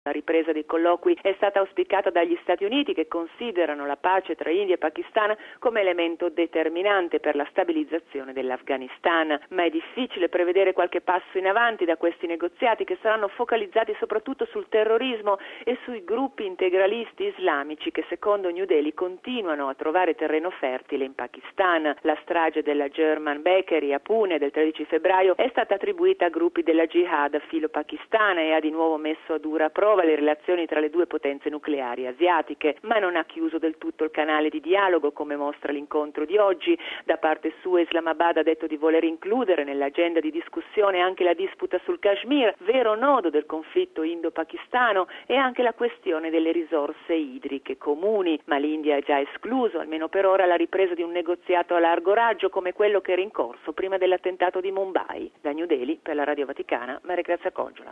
Per oggi è prevista la ripresa dei colloqui tra alti rappresentanti diplomatici dei due Paesi, anche se molte restano le questioni irrisolte. Da Nuova Delhi